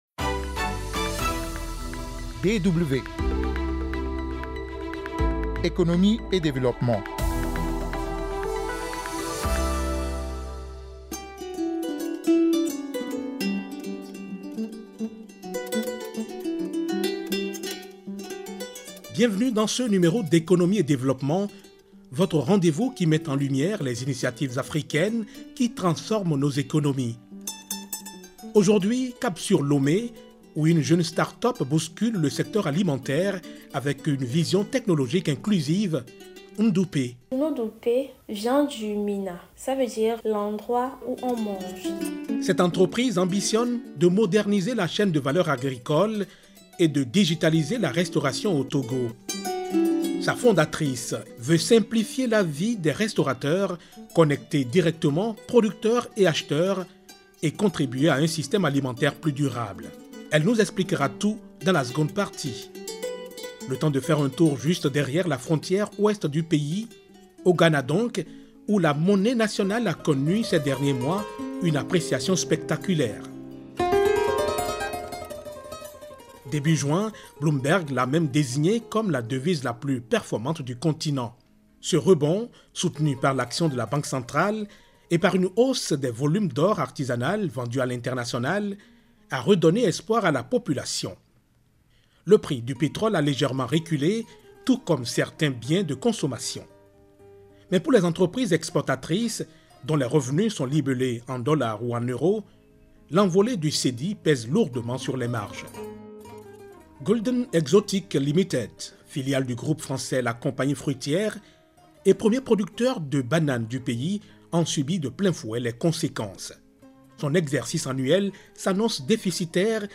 Des analyses, des interviews et des reportages pour comprendre les évolutions actuelles, en Afrique et ailleurs.